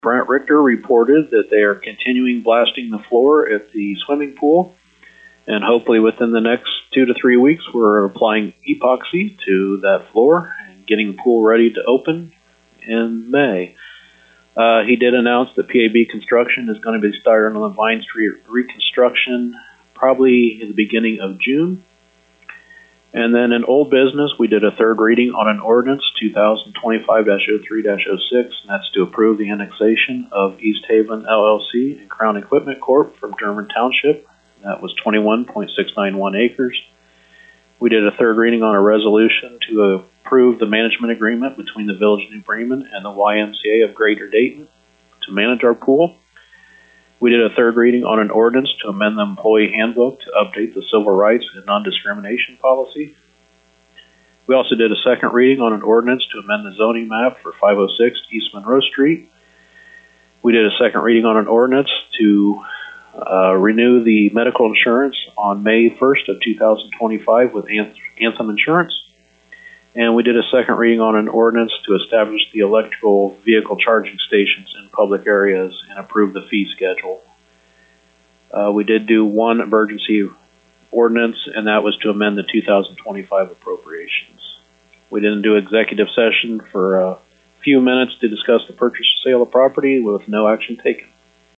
For the summary with New Bremen Mayor Bob Parker: